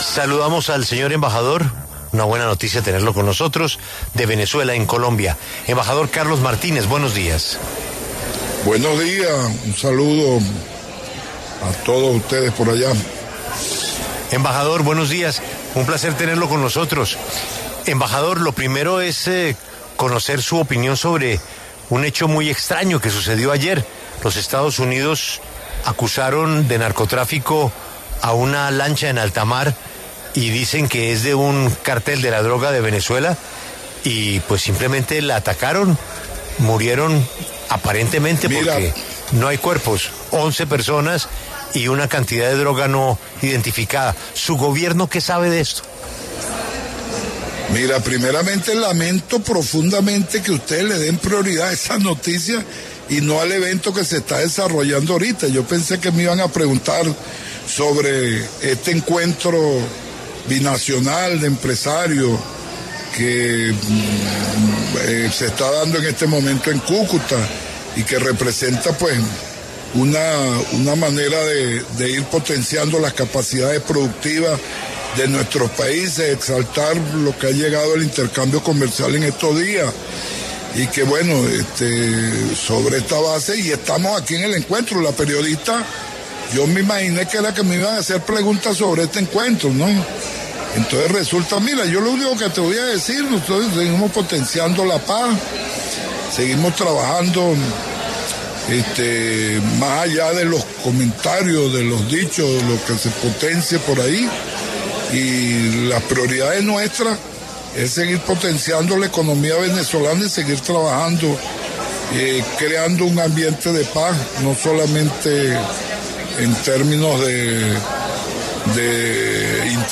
Carlos Martínez, embajador de Venezuela en Colombia, conversó con La W sobre el Encuentro Binacional: Visión Frontera 2025.